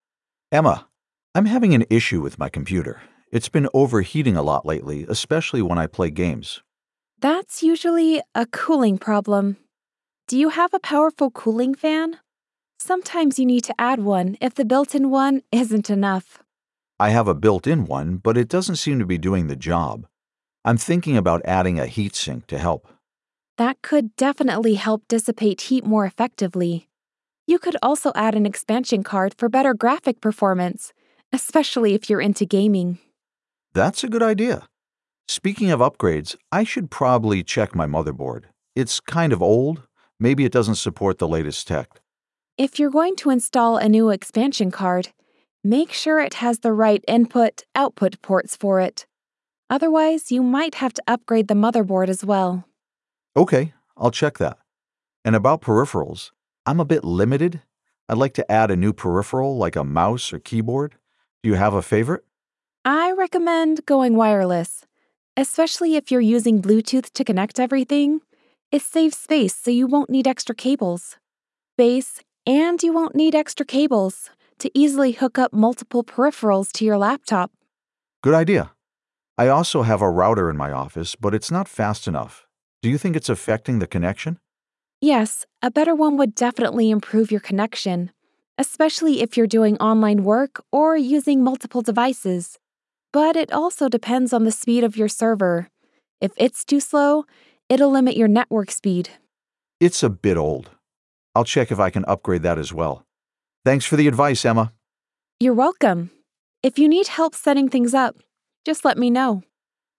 E-Learning - Duo
Middle Aged
My voice has been described as warm, trustworthy, and confident.